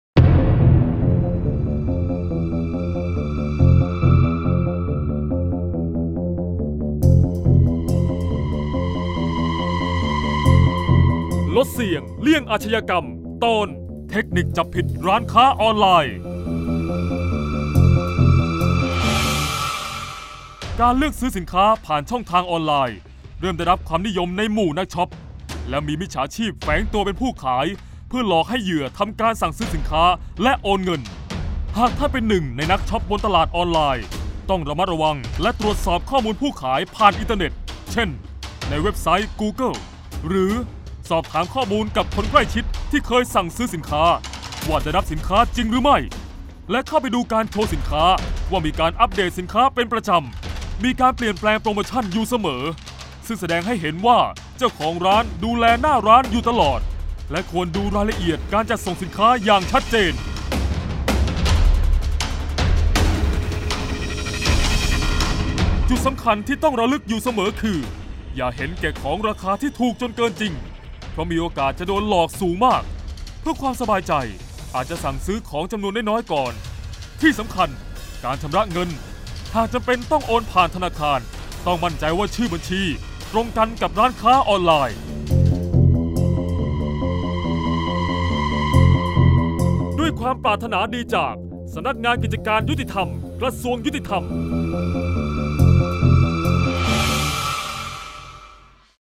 เสียงบรรยาย ลดเสี่ยงเลี่ยงอาชญากรรม 07-เทคนิคจับผิดร้านค้าออนไลน์